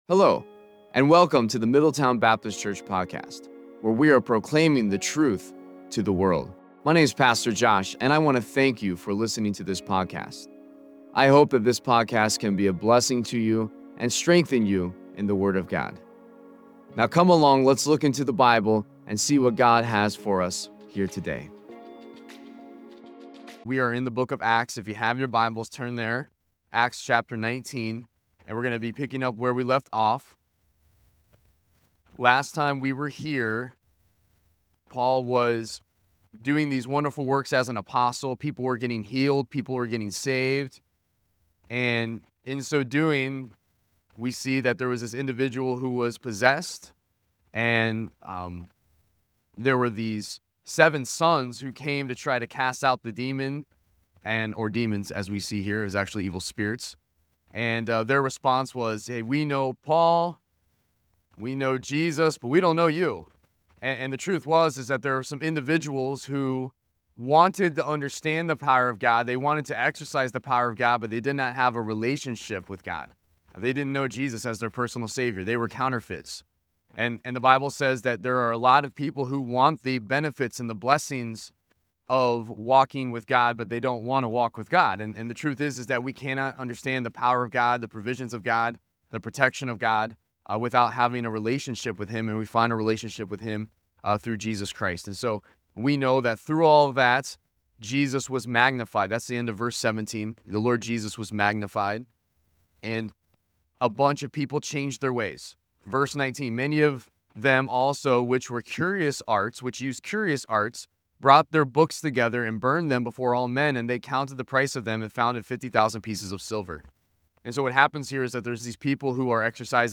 The pastor’s introduction sets a solemn and reflective tone, inviting listeners to engage deeply with the scripture as they embark on a journey through the narrative of the early church. He emphasizes the necessity of a genuine relationship with Jesus Christ, asserting that true authority and understanding of God's power are unattainable without such a connection.
Worship-Service-April-6-2025.mp3